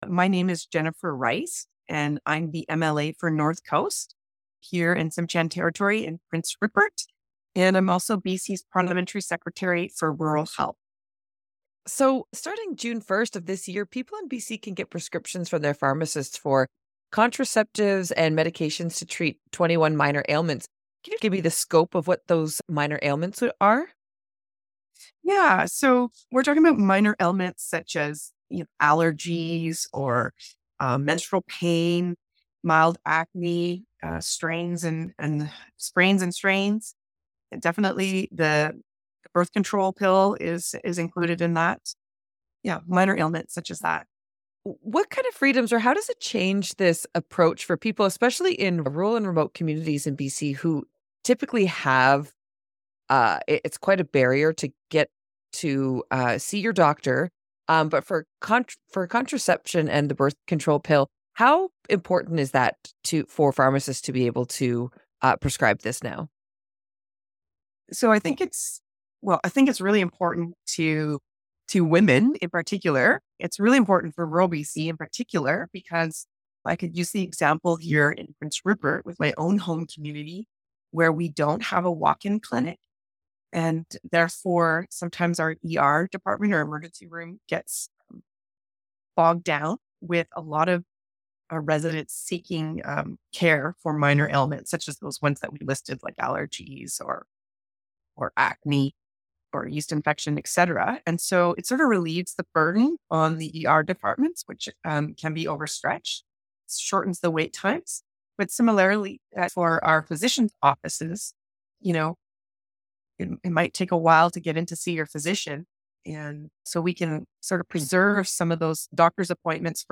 BC Parliamentary Secretary for Rural Health talks about new prescribing powers for pharmacists